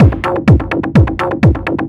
DS 126-BPM B6.wav